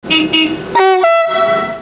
IC3 sjovt horn (direkte lydlink)
Hurup Thy
ic3sjovthorn.mp3